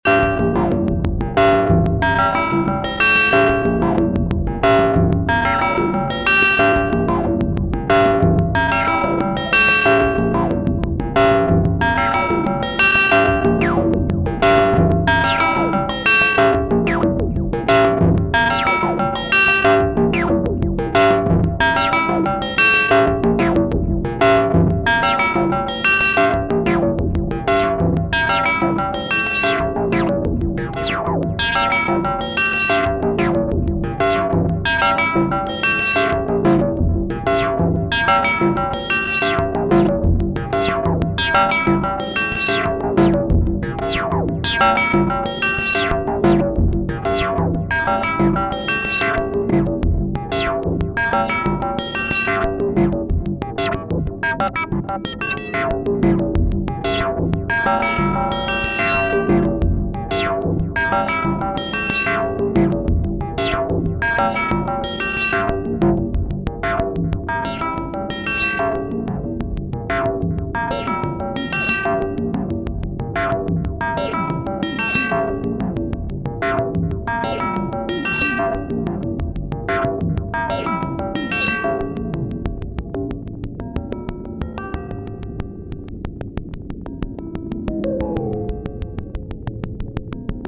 Moog Muse - 8 Voice polyphonic Synthesizer V1.4
So, hier noch die versprochene Resonanz Version links und rechts jeweils die beiden Filter, es geht hier also nur um Variation - gleiches Muster wie oben, nur eben mit Resonanz Filter 1, dann 2.
Moog Muse Resonanz.mp3